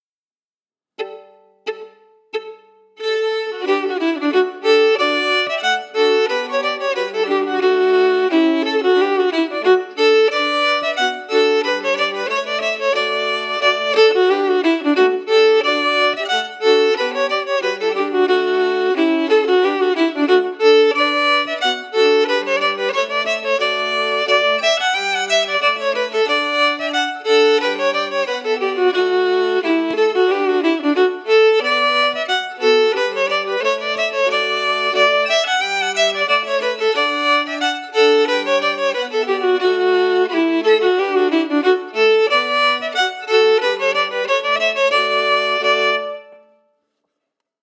Key: D
Form: Reel/Song
Melody emphasis
M: 4/4